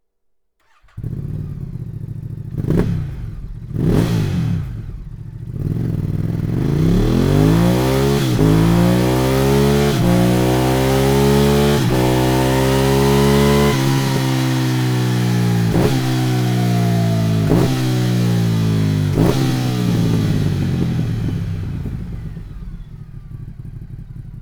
Sound Akrapovic Slip-On